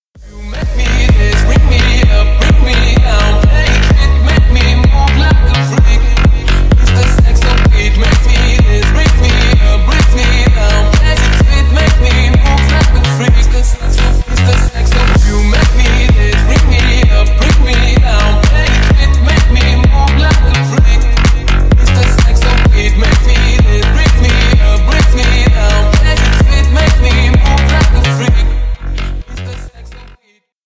бесплатный рингтон в виде самого яркого фрагмента из песни
Танцевальные
клубные